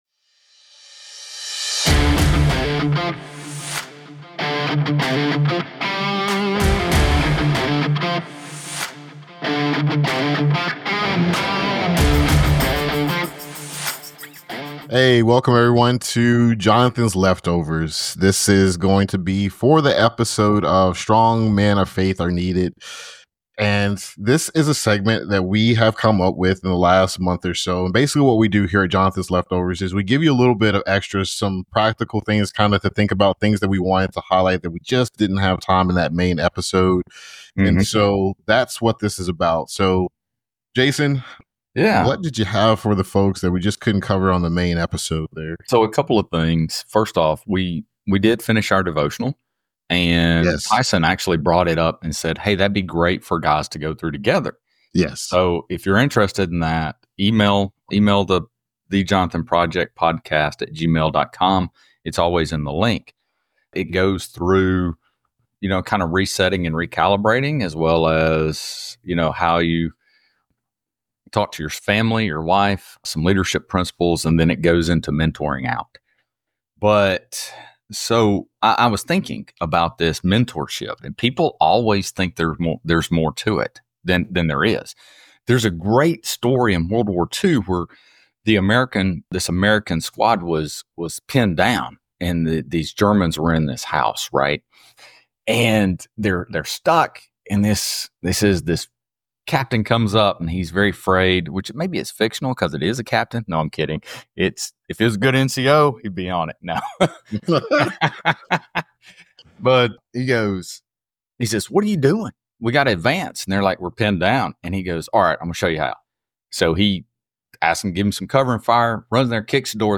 This week's bonus material dives into a discussion on why men of strong faith are needed in modern society. Join us as we fill your plate with a bit more from the Jonathan Project Podcast and dive into conversation on men standing in the gaps of society and leading from a Biblical perspective.